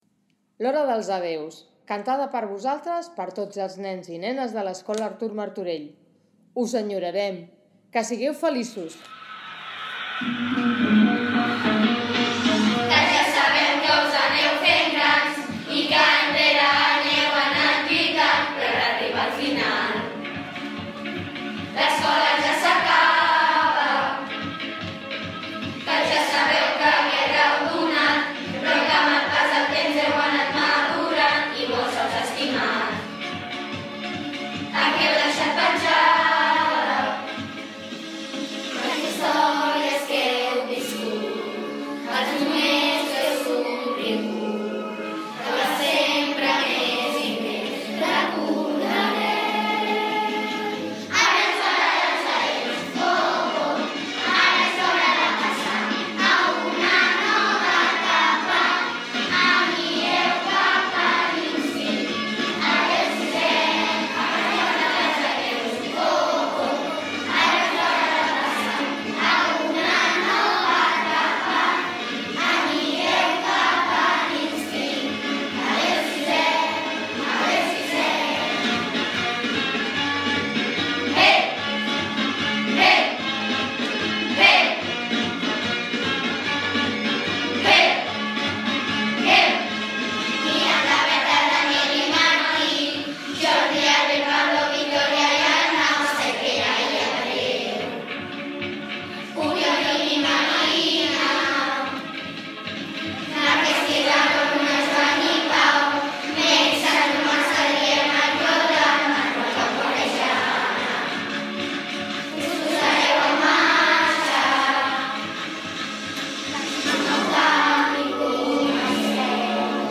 Perquè tinguin un bon record, la resta de companys i companyes de l’escola els hem fet aquesta cançó:
lhora-dels-adéus-cançó.mp3